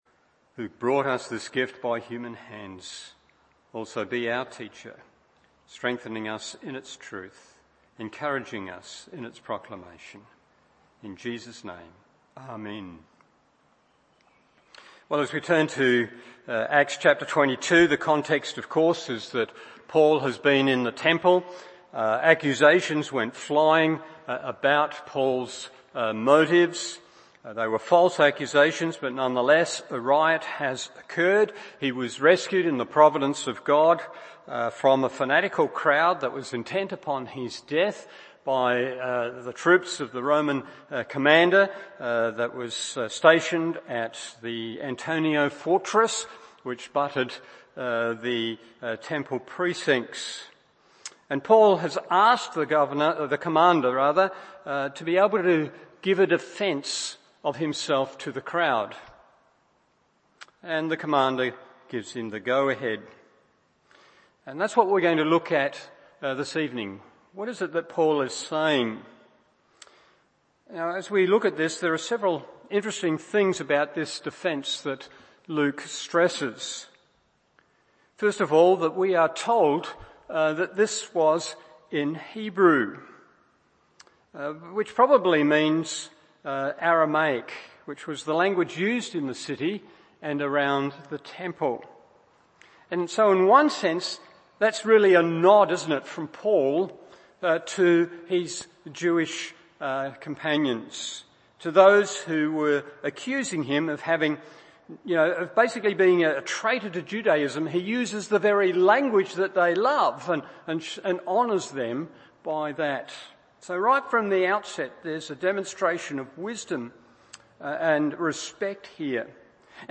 Evening Service Acts 22:1-24 1.